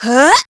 Ripine-Vox_Attack2.wav